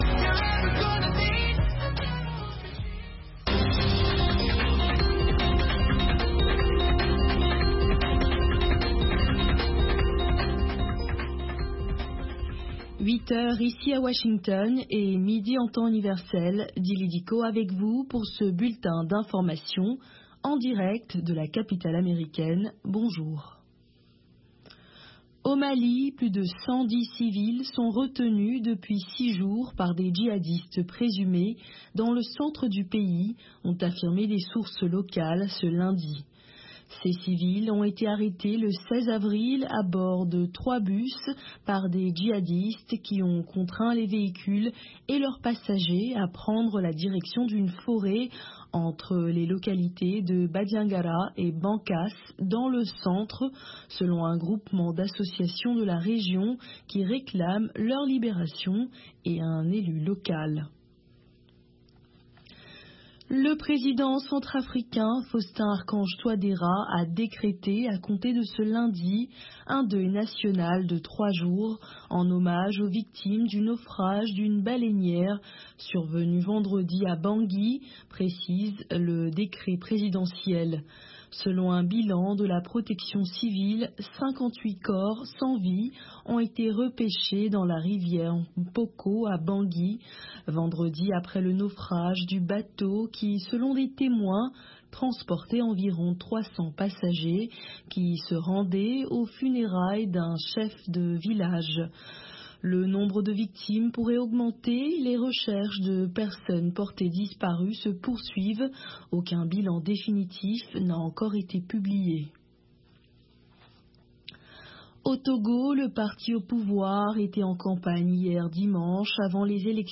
Bulletin d'information de 16 heures
Bienvenu dans ce bulletin d’information de VOA Afrique.